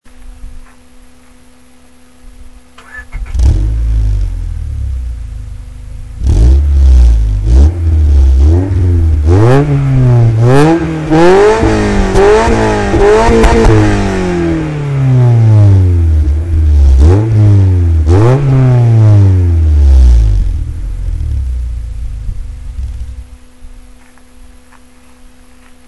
Nicht leise aber geil! :D
Klingt zwar nicht halbsogut wie in Wirklichkeit, aber immerhin etwas...